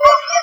motor.wav